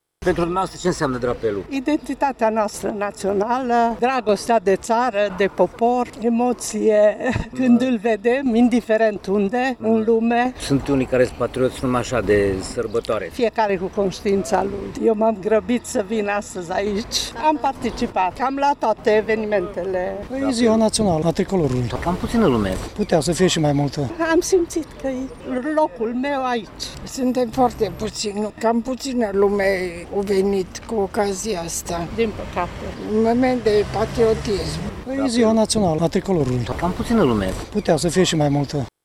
Înafara oficialităților, militarilor și a preoților, puțini cetățeni s-au alăturat ceremoniei, iar oamenii și-ar fi dorit ca patriotismul să nu fie afișat doar cu prilejul festivităților: